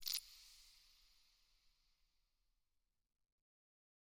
eyeblossom_open3.ogg